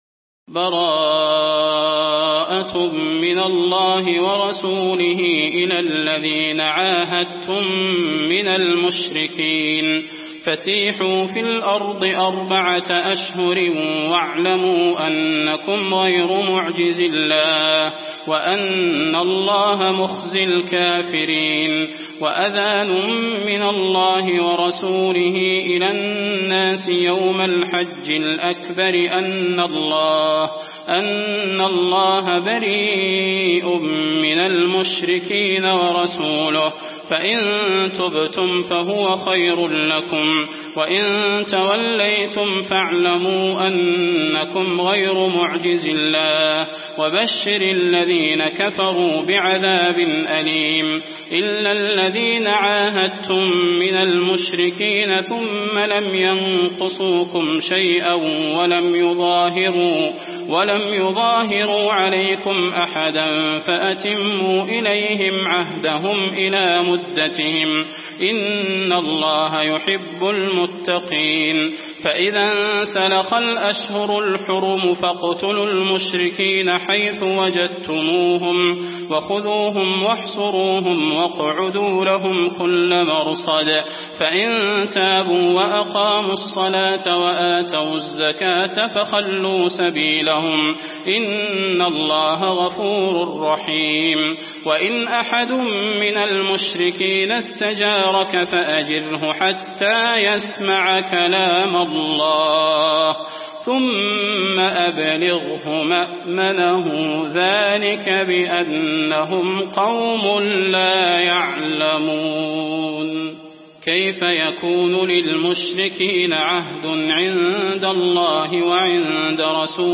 المكان: المسجد النبوي التوبة